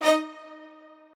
strings3_2.ogg